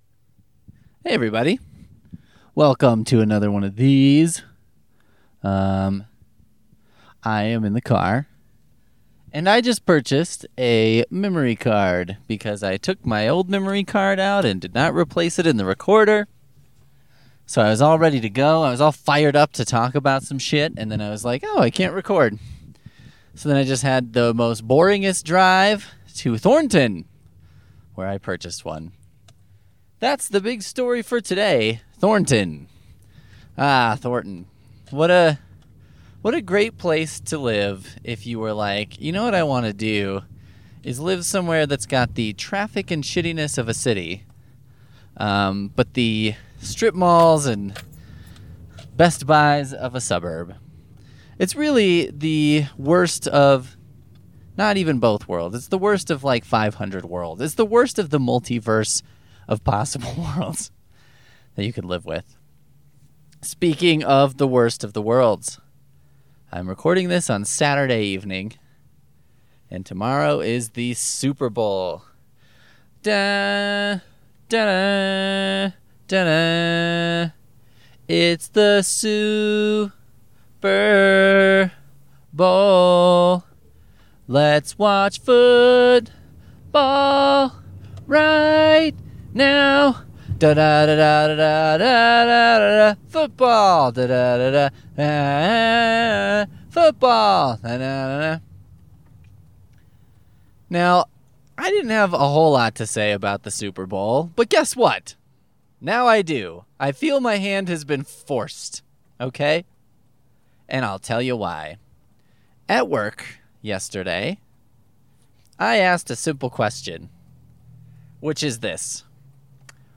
We had severe technical difficulties that resulted in no theme music.